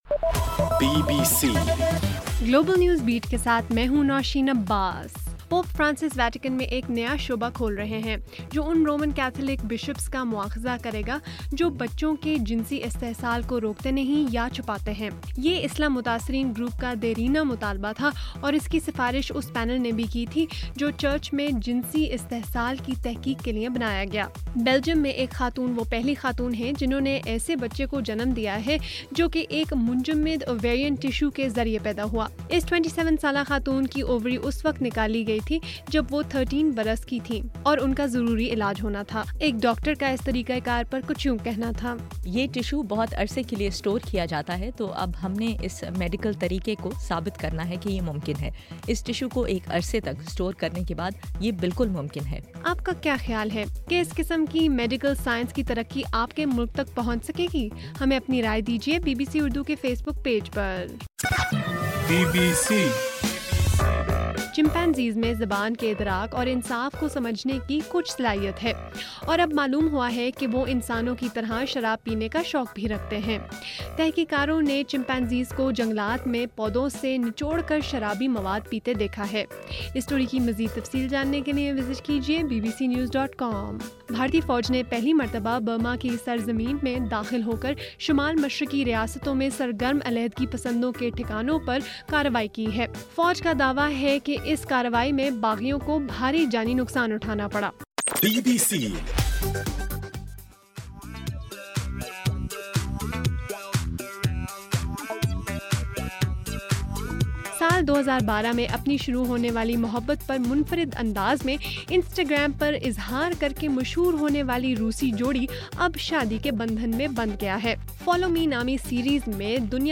جون 10: رات 9 بجے کا گلوبل نیوز بیٹ بُلیٹن